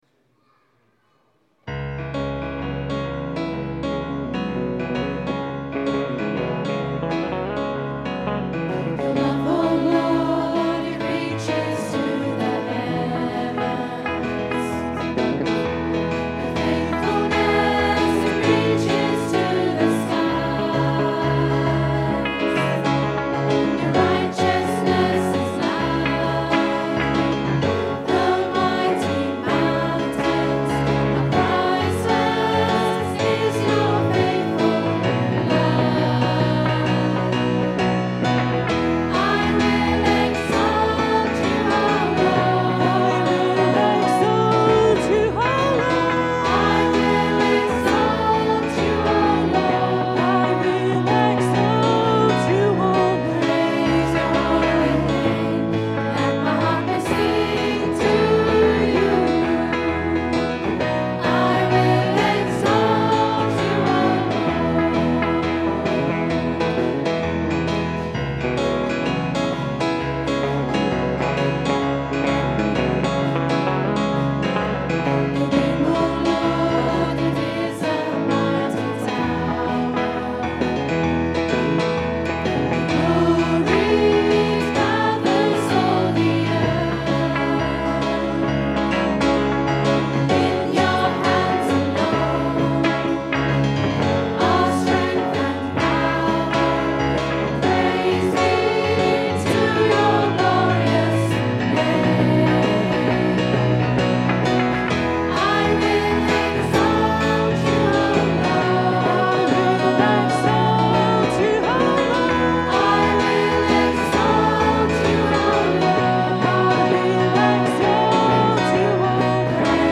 Recorded on a Zoom H4 digital stereo recorder at 10am Mass on 18th July 2010.